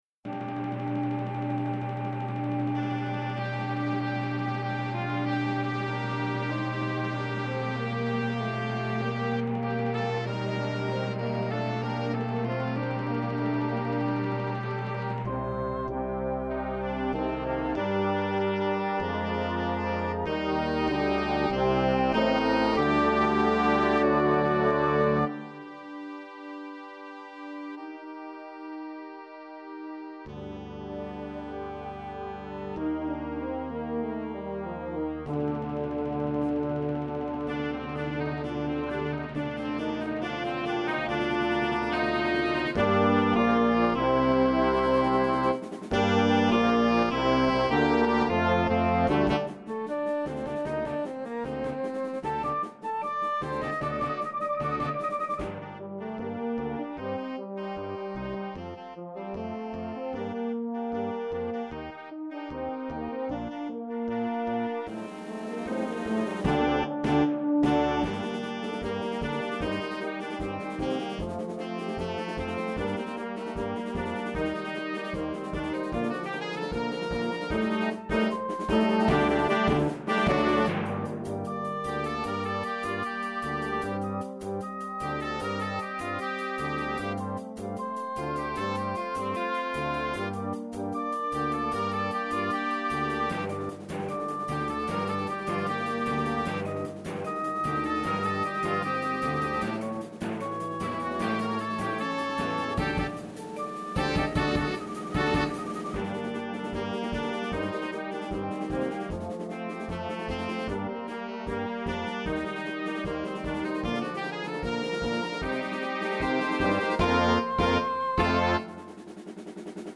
Gattung: Flexible Besetzung
Besetzung: Blasorchester